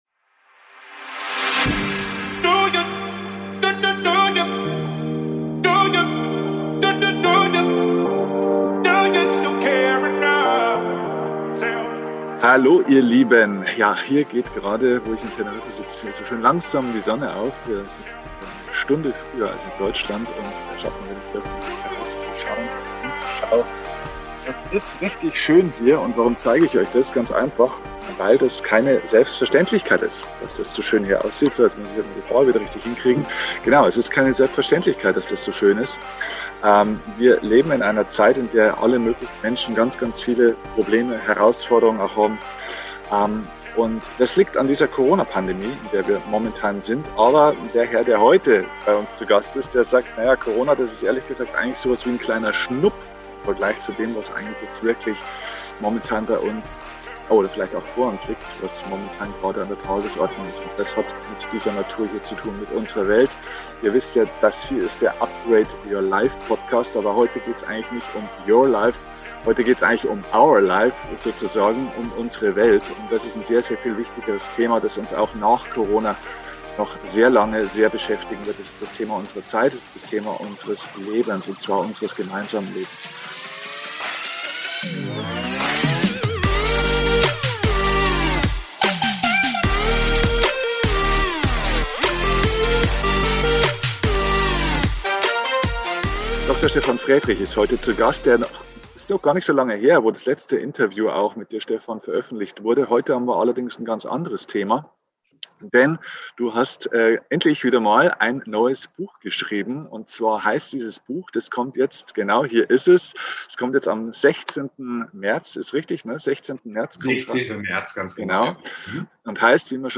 #379 Unser Planet stirbt! Was können wir tun Interview mit Dr. Stefan Frädrich Dr. med.